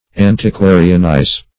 Search Result for " antiquarianize" : The Collaborative International Dictionary of English v.0.48: Antiquarianize \An`ti*qua"ri*an*ize\, v. i. To act the part of an antiquary.
antiquarianize.mp3